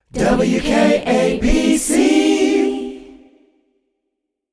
A cappella